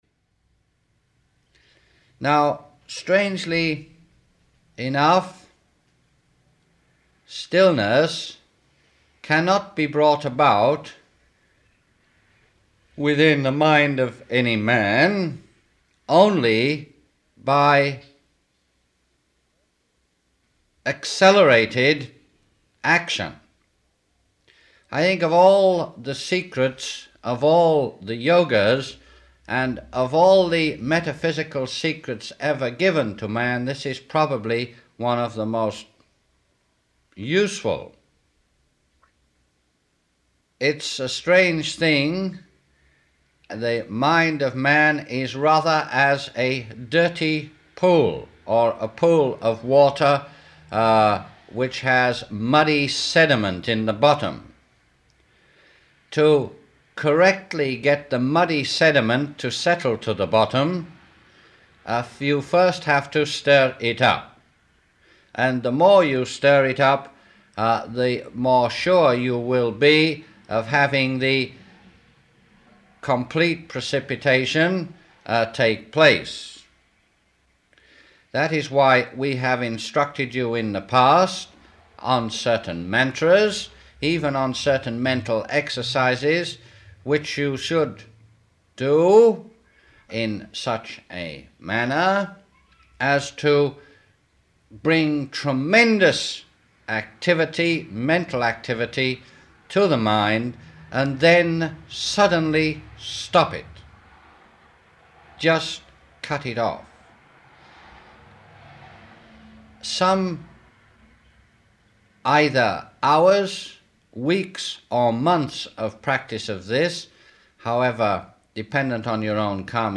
Rarely is so much profound knowledge and advice available in so few words. The entire system of yoga is encapsulated in this one sentence delivered by the great Cosmic Avatar known as Mars Sector 6. In this address